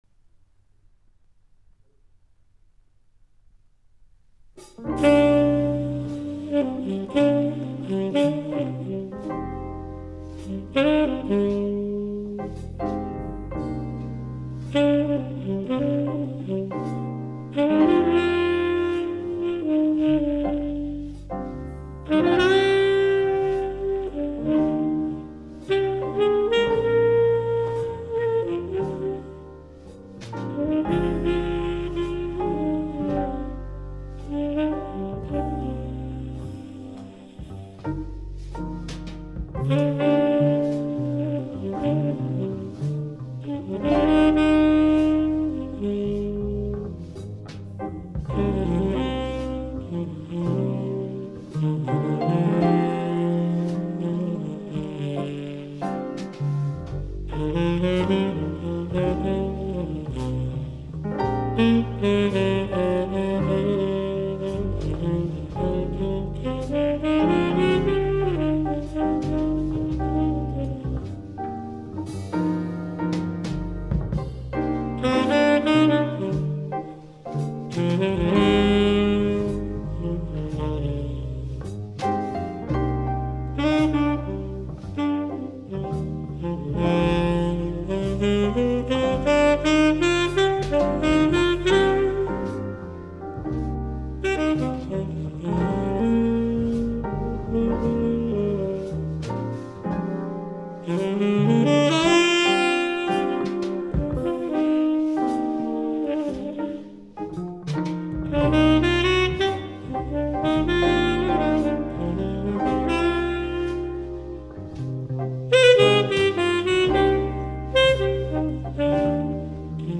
Coole Sounds mit Einflüssen aus verschiedenen Kulturen